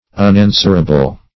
Unanswerable \Un*an"swer*a*ble\, a.